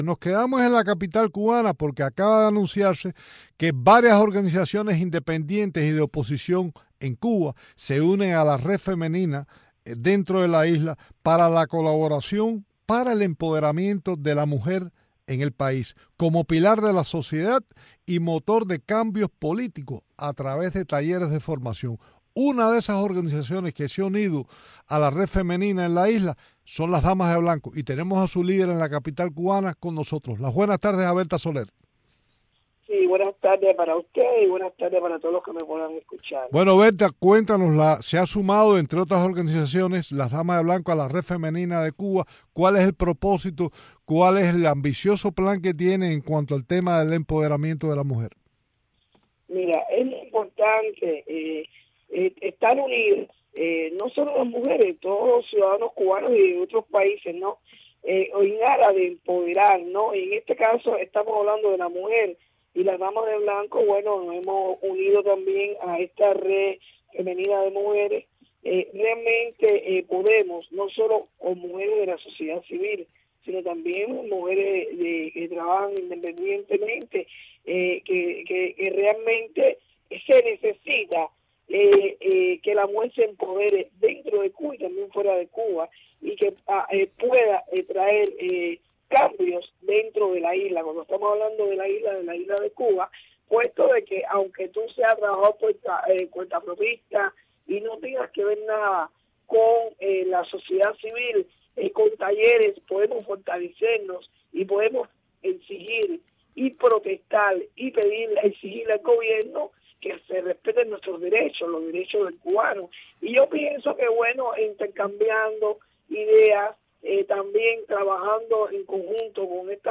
Entrevista a Berta Soler | red Femenina de Cuba